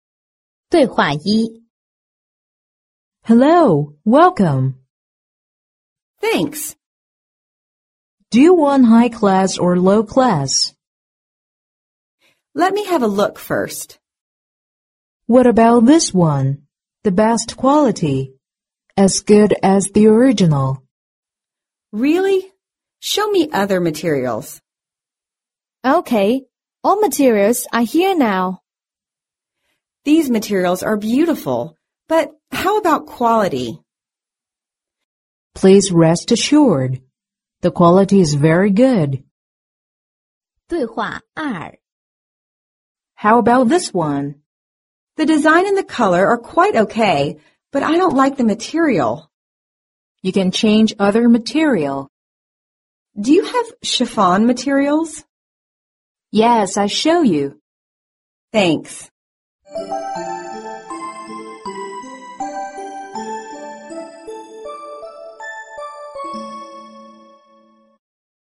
外贸服装口语 第12期:服装面料之情景对话 听力文件下载—在线英语听力室